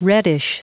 Prononciation du mot reddish en anglais (fichier audio)
Prononciation du mot : reddish